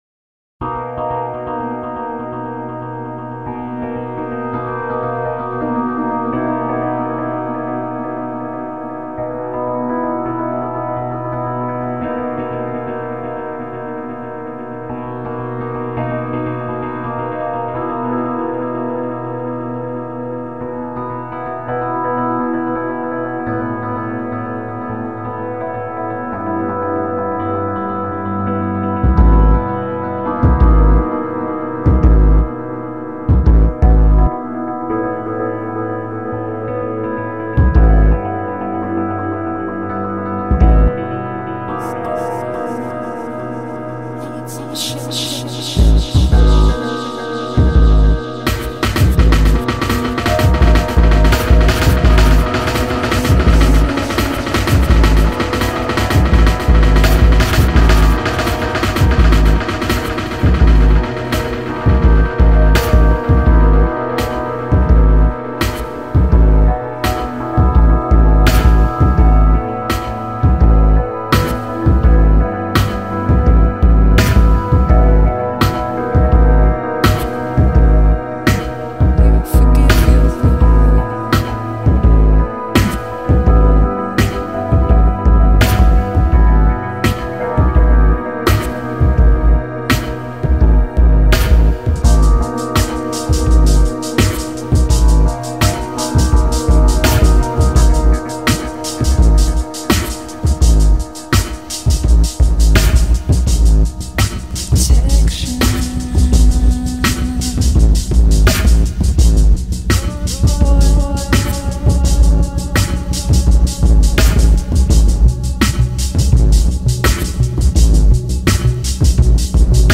Dub, Trip-Hop, Electronic